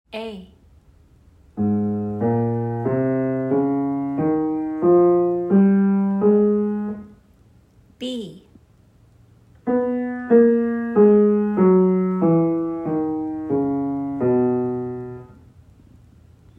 Example A shows the added accidentals after applying the whole steps and half steps from the previous steps. Added accidentals include B-flat, D-flat, and E-flat.